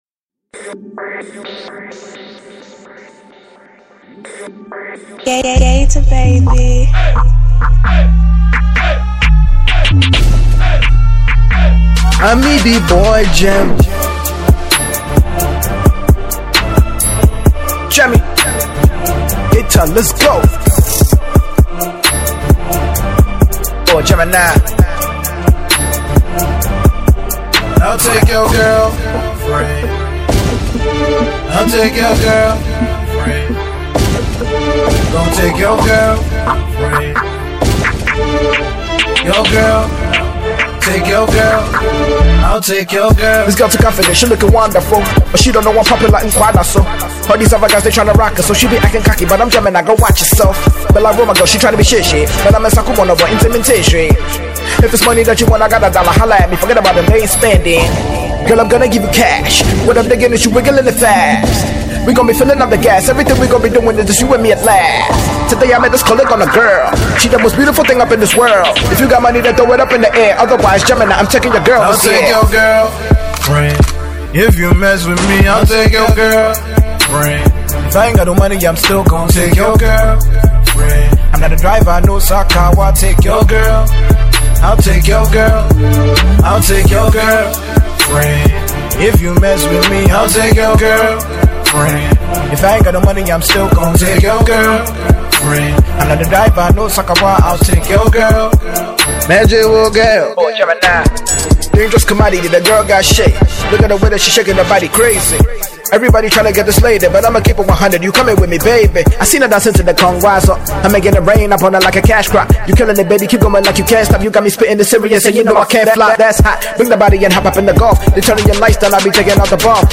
on a Hiphop beat